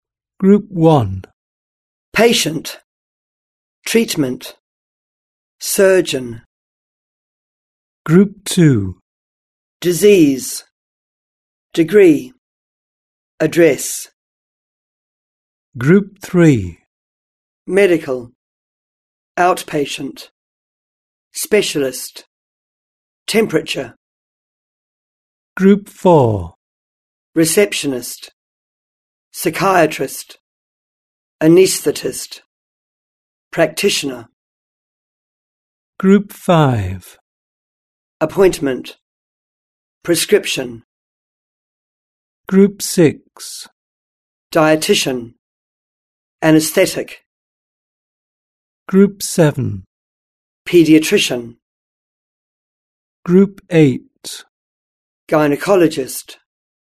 3. Pronunciation: Word stress.